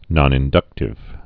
(nŏnĭn-dŭktĭv)